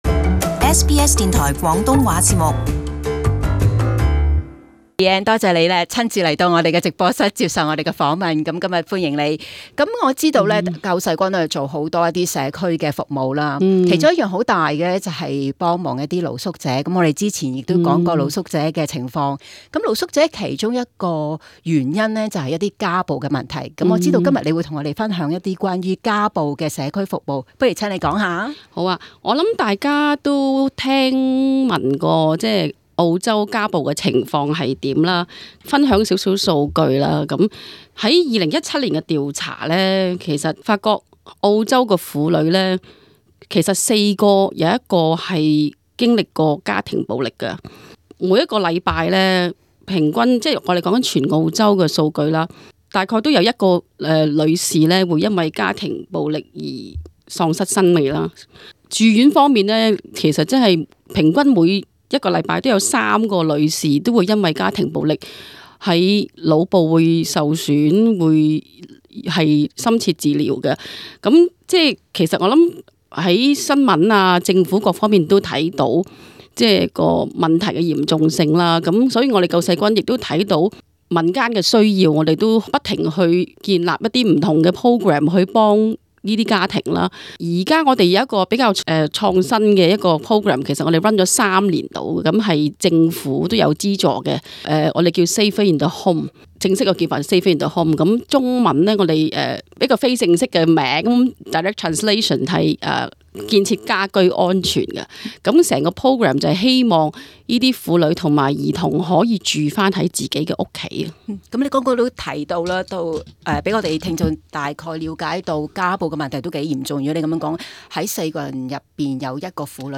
【社區專訪】保障家暴受害人在家居住安全計劃